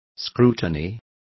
Complete with pronunciation of the translation of scrutiny.